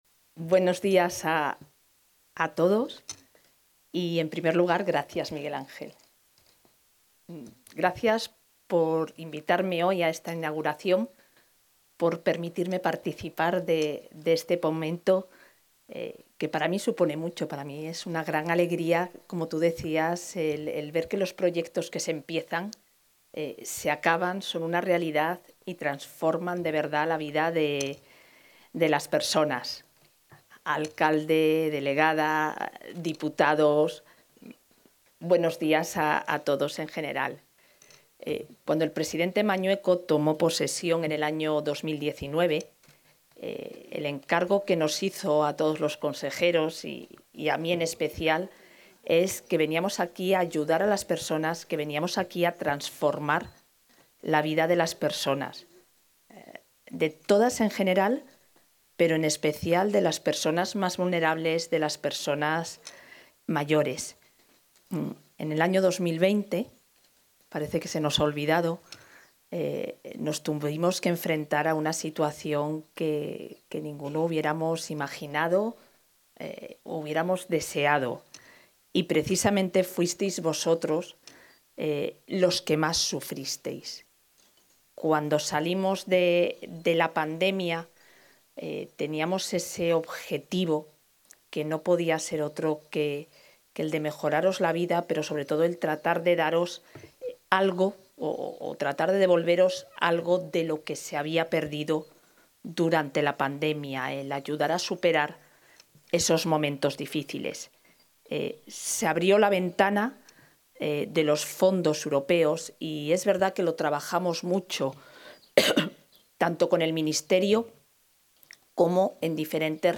Declaraciones de la vicepresidenta.